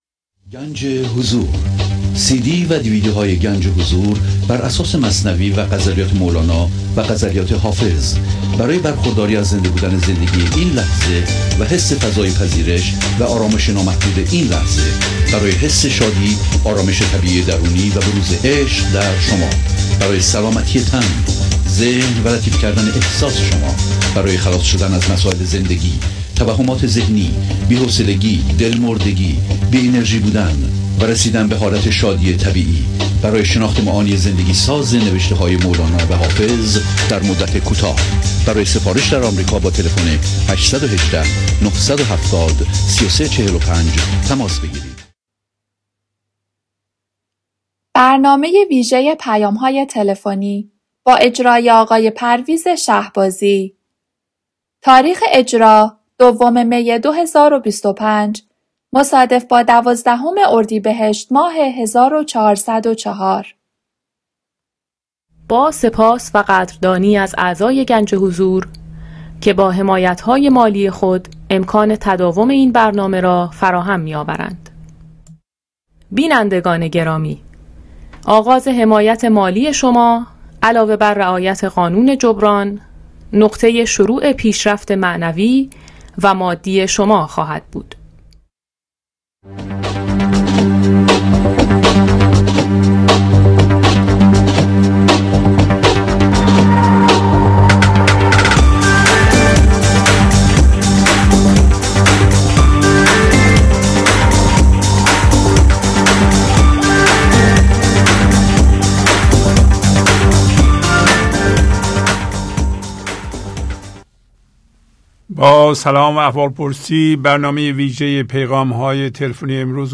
Phone Calls Audio Programs #1003-3.